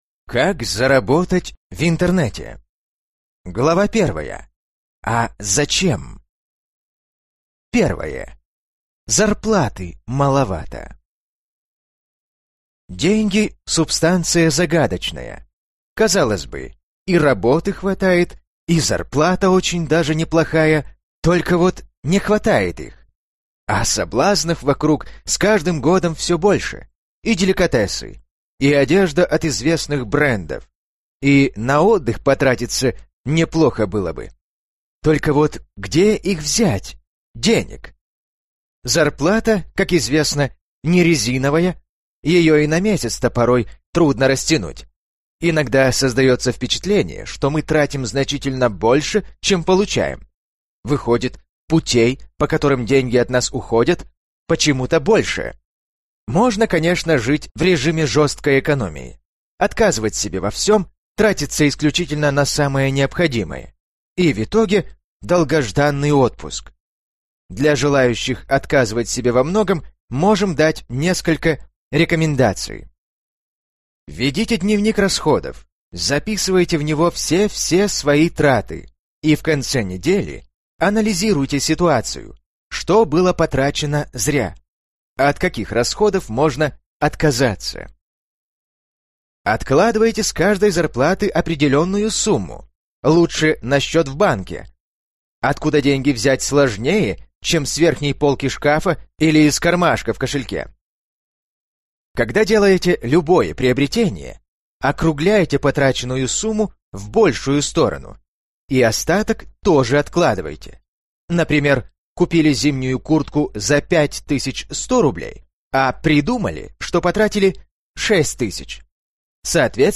Аудиокнига Как заработать в Интернет | Библиотека аудиокниг